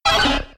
Cri de Têtarte K.O. dans Pokémon X et Y.